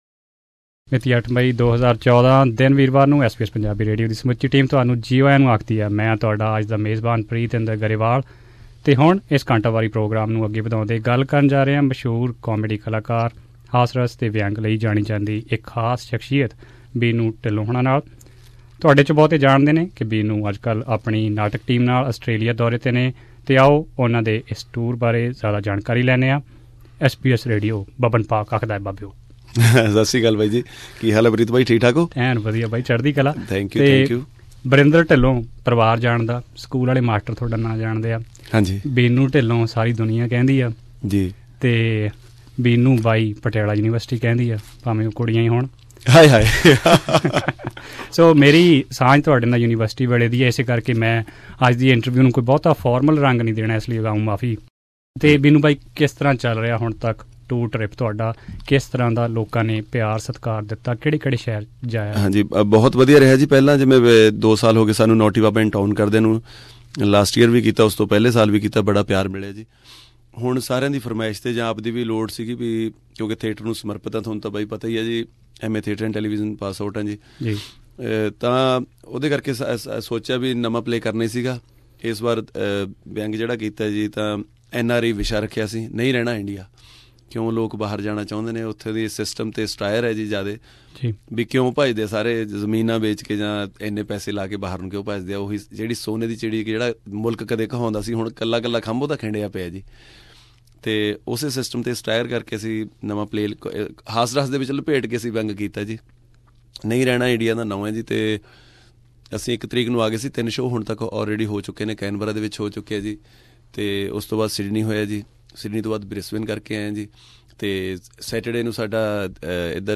Interview with Punjabi comedian Binnu Dhillon
Binnu Dhillon and friends at SBS Studio